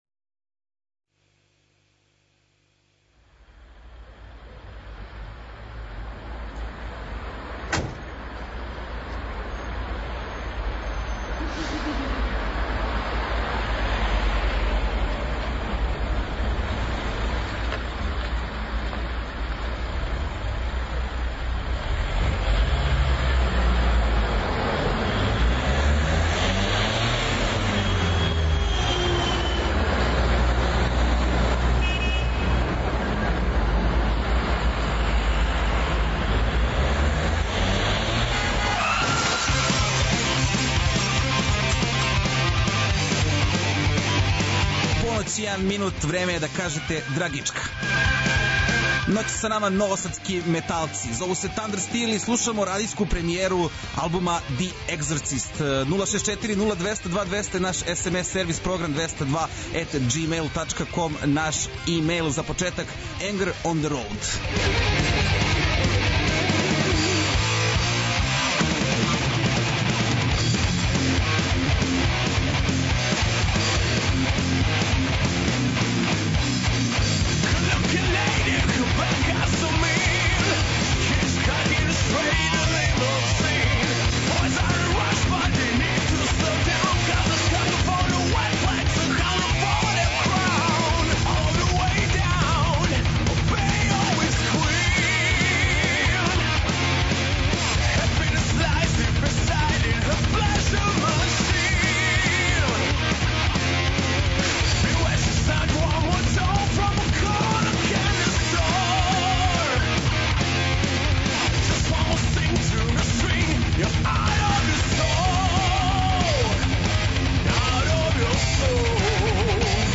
Ноћас са нама новосадски металци - 'THUNDERSTEEL', који промовишу свој деби албум 'THE EXORCISM' и најављују наступе на којима ће промовисати нове песме, међу којима су EXIT фестивал и наступ са групом 'Дивље јагоде'.